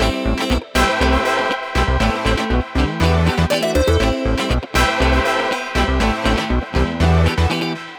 23 Backing PT4.wav